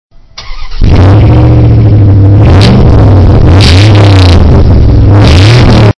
TRUCK1.mp3